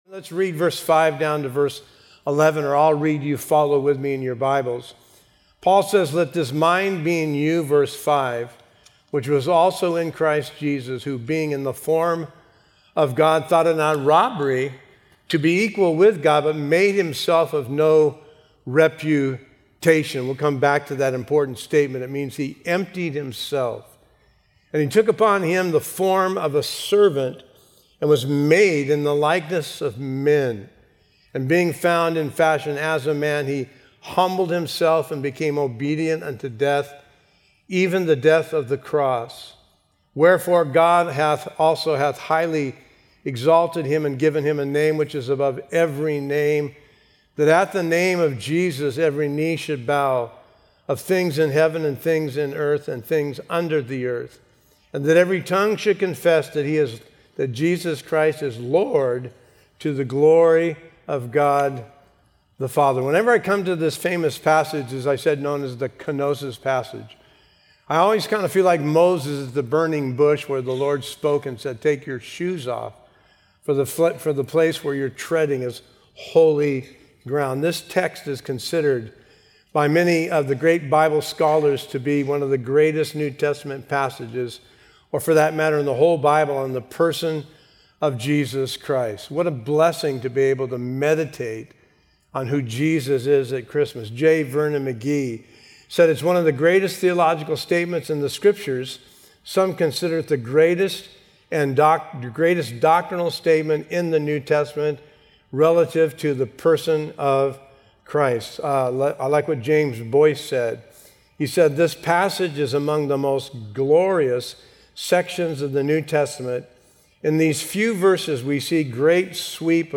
A verse-by-verse sermon through Philippians 2:5-11